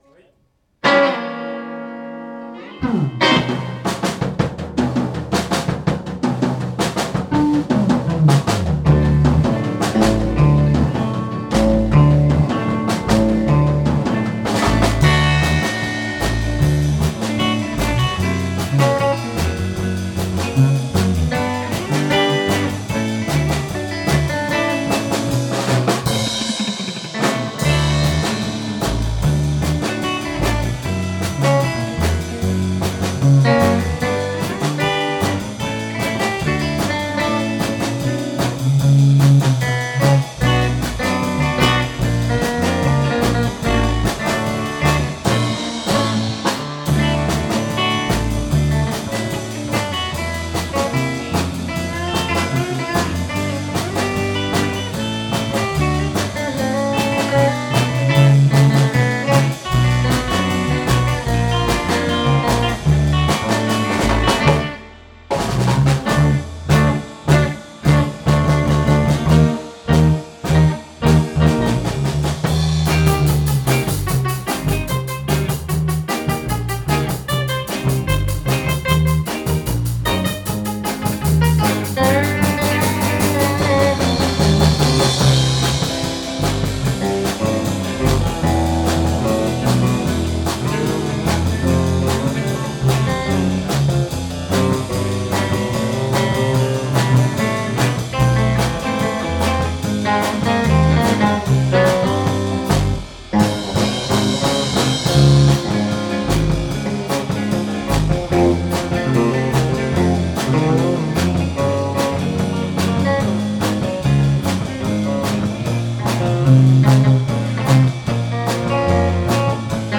6月3日(土）「ベンチャーズハウス六絃」にて「2017 Jun Live」を開催しました
飛び入り演奏の皆さん